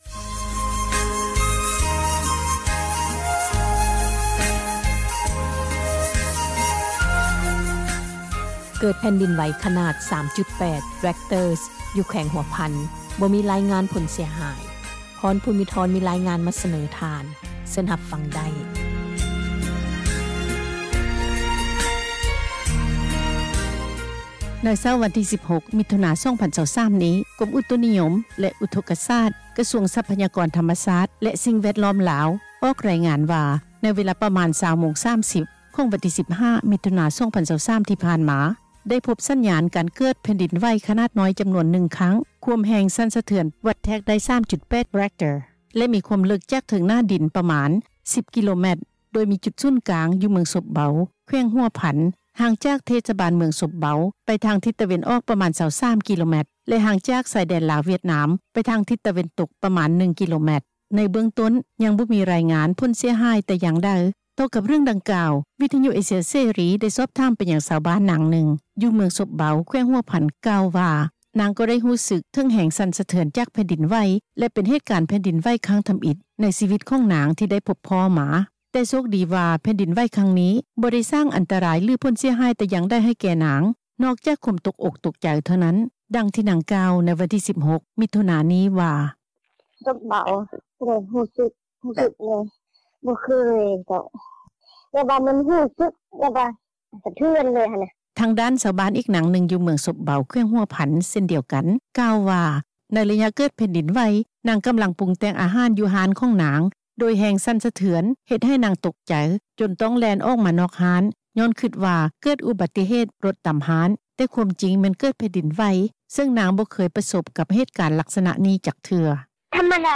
ຕໍ່ກັບເຣື່ອງດັ່ງກ່າວ, ວິທຍຸ ເອເຊັຽ ເສຣີ ໄດ້ສອບຖາມໄປຍັງ ຊາວບ້ານ ນາງນຶ່ງ ຢູ່ເມືອງສົບເບົາ ແຂວງຫົວພັນ ກ່າວວ່າ ນາງກໍ່ໄດ້ຮູ້ສຶກເຖິງແຮງສັ່ນສະເທືອນ ຈາກແຜ່ນດິນໄຫວ ແລະ ເປັນເຫດການແຜ່ນດິນໄຫວຄັ້ງທຳອິດ ໃນຊີວິດທີ່ນາງໄດ້ພົບພໍ້ມາ, ແຕ່ໂຊກດີວ່າແຜ່ນດິນໄຫວຄັ້ງນີ້ ບໍ່ໄດ້ສ້າງອັນຕະຣາຍ ຫຼືຜົລເສັຽຫາຍຢ່າງໃດ, ນອກຈາກຄວາມຕົກໃຈທໍ່ນັ້ນ.